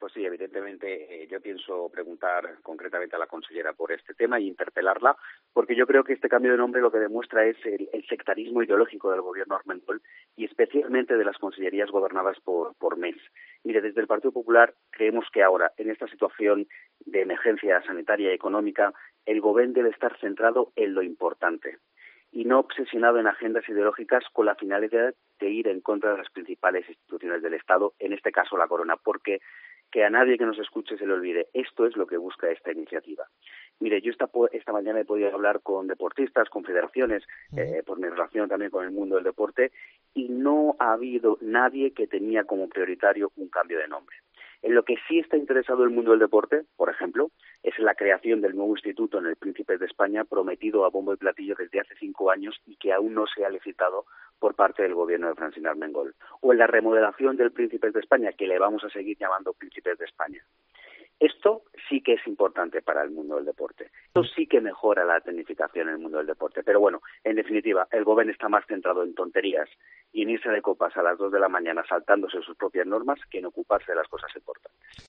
Hoy en Deportes Cope Mallorca, Toni Fuster, secretario general del PP balear, ha hecho una valoración sobre el cambio del nombre del poliesportiu Príncipes de España que ahora se llamará Centro de Tecnificación Islas Baleares (Centre de tecnificació Illes Balears).